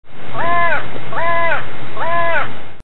hooded crow.